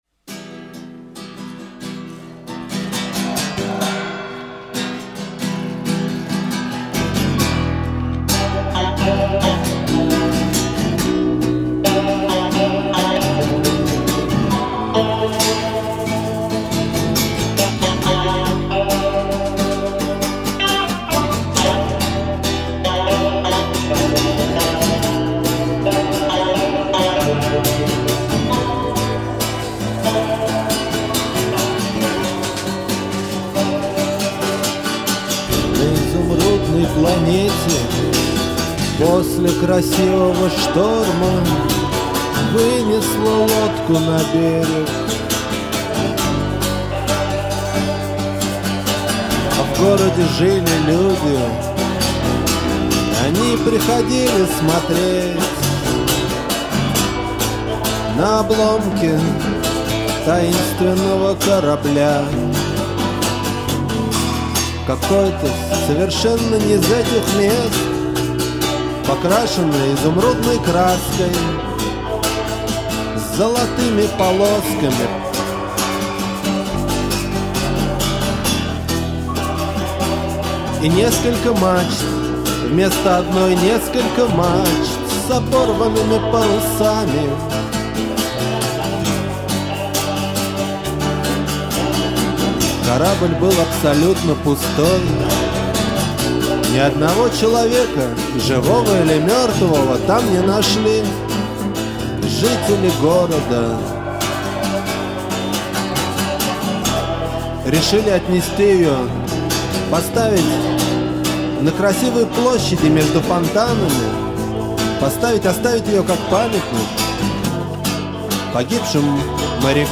вокал.
клавиши, гитара.
бас.
перкуссия, ударные.